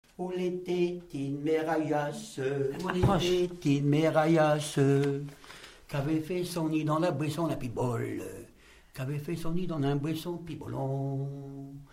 Localisation Herbiers (Les)
Genre laisse
Catégorie Pièce musicale inédite